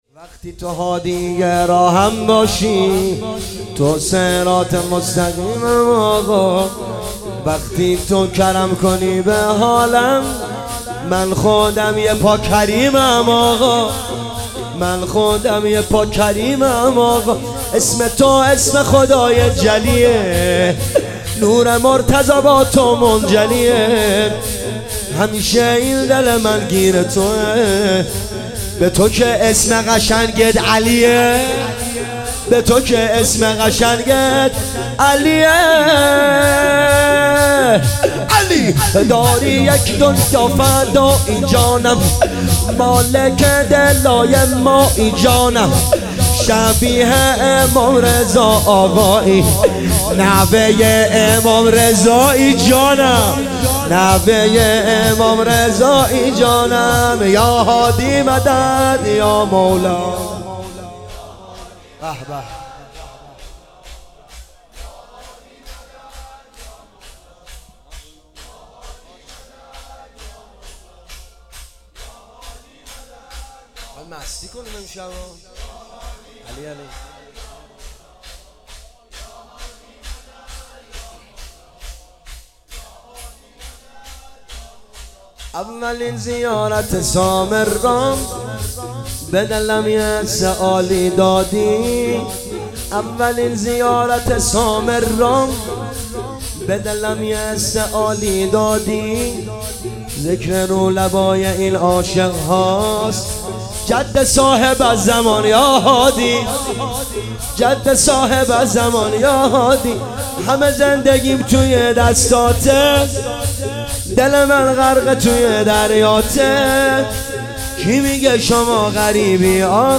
مراسم زنجیرزنی عزاداران امام هادی (ع) 98 - شور - وقتی تو هادیِ راهم باشی